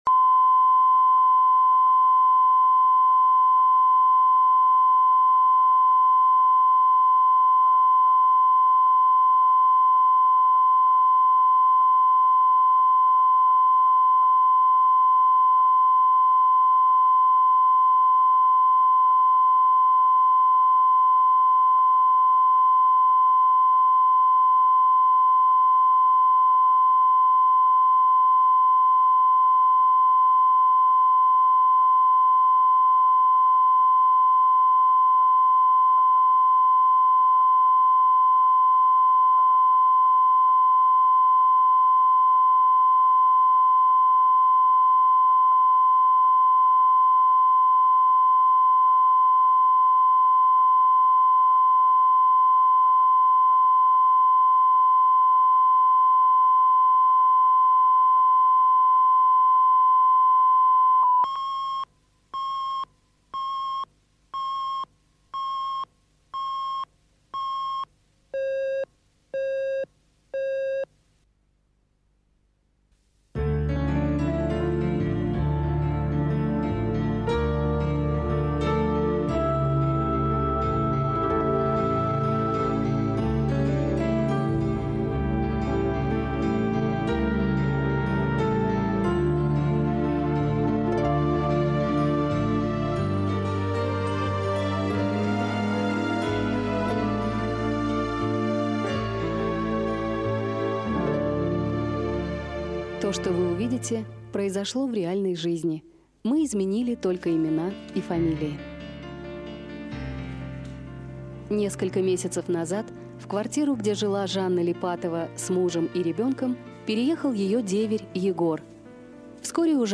Аудиокнига Между страхом и любовью | Библиотека аудиокниг